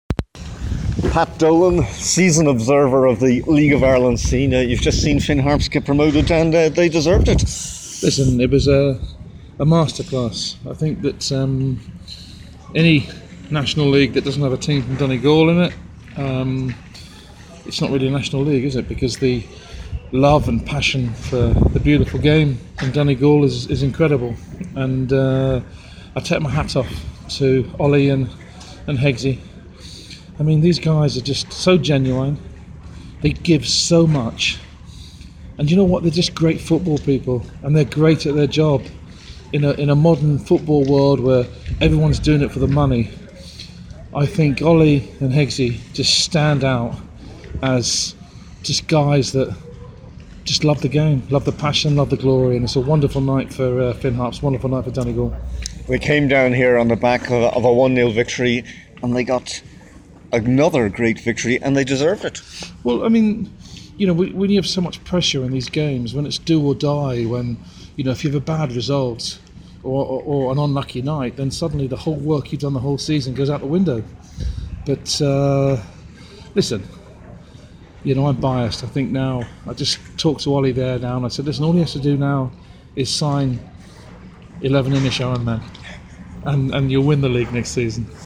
He spoke to Highland Radio after the game.